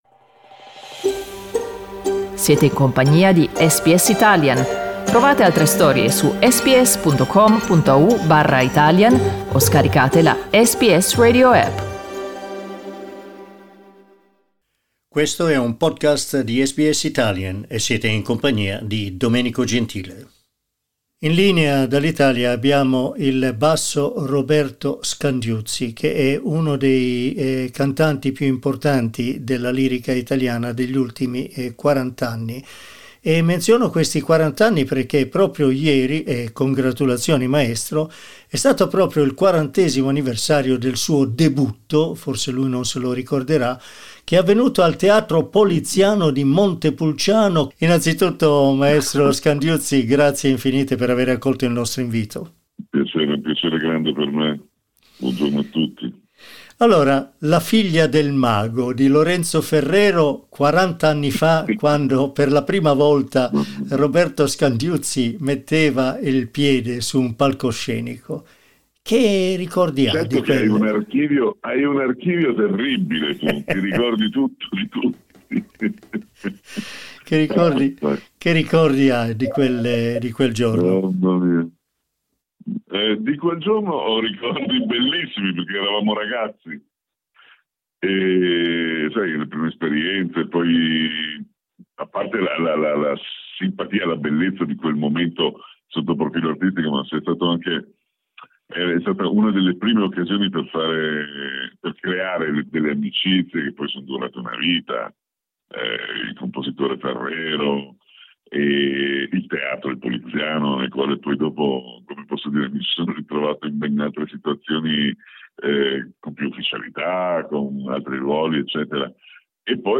"Ho impegni fino al 2024", ammette ai microfoni di SBS Italian spiegando che, oltre alla sua attività artistica, da anni segue anche la carriera di insegnante di canto.